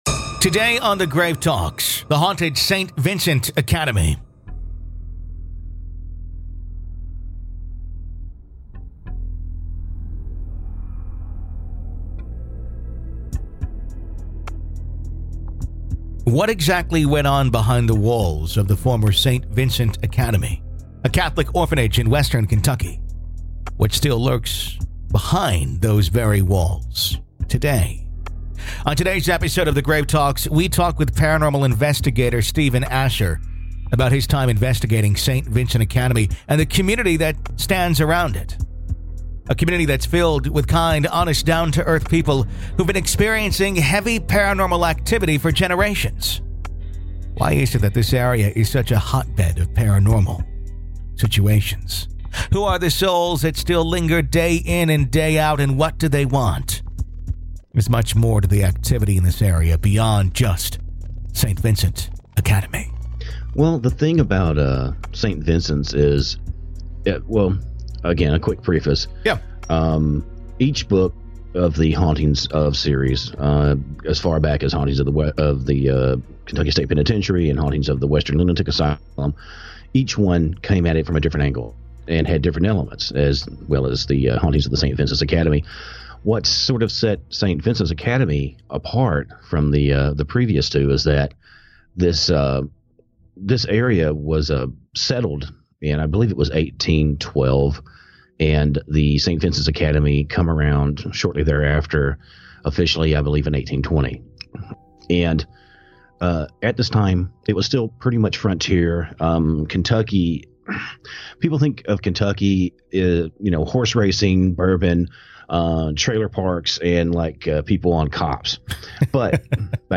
To listen to part two of our interview, you need to be a Grave Keeper (supporter of the show).